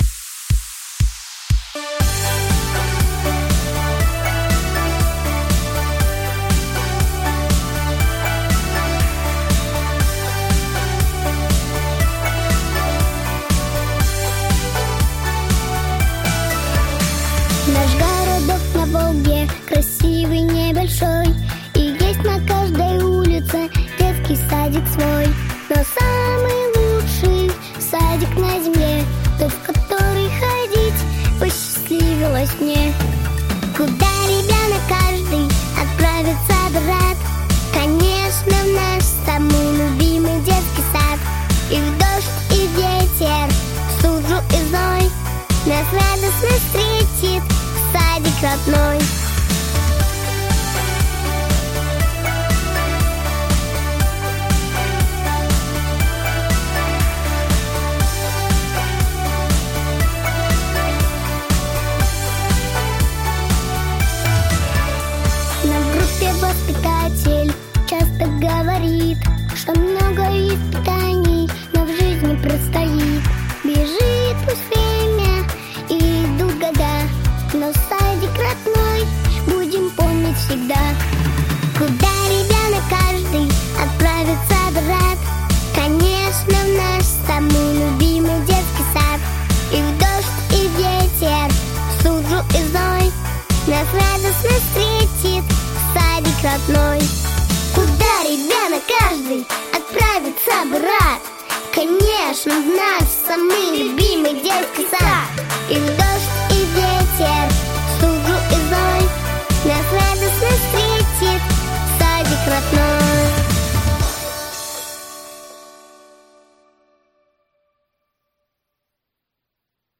• Категория: Детские песни
в исполнении девушек